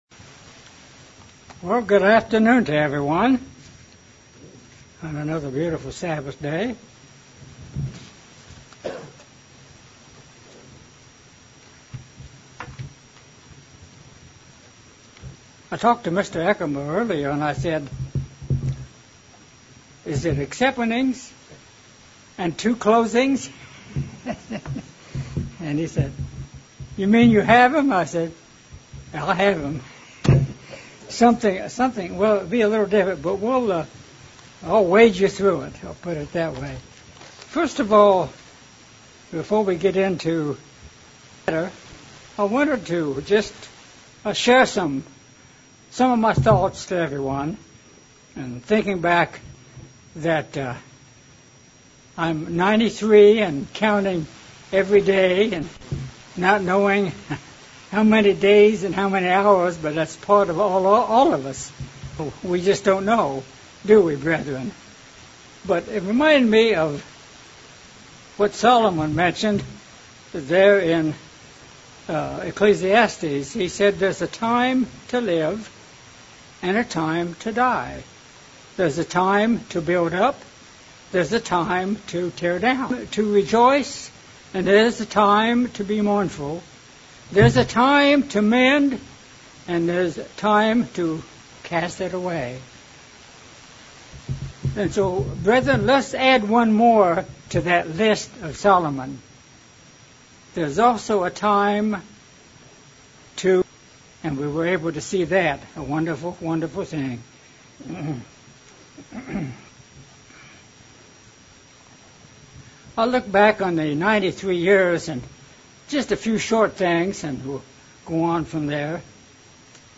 Sermon looking at the qualities of wisdom and understanding in Proverbs Chapter 1 and asking where can wisdom be found?